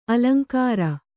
♪ alaŋkāra